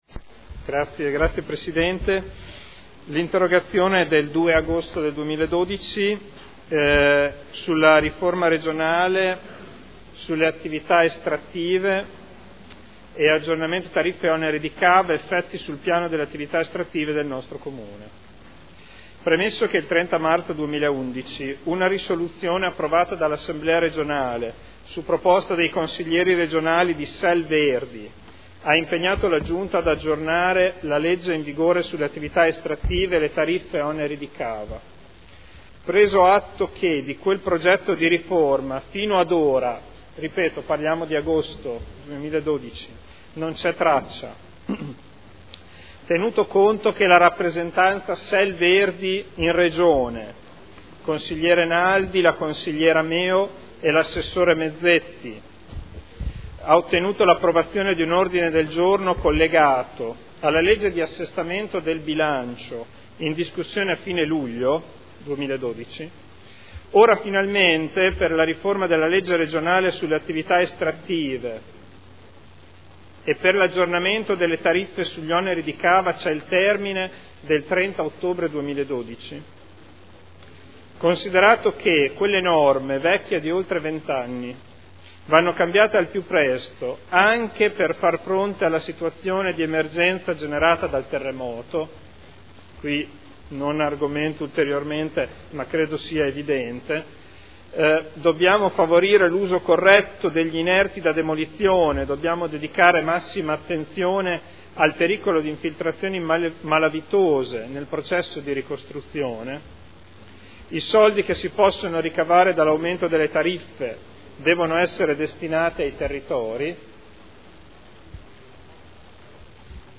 Federico Ricci — Sito Audio Consiglio Comunale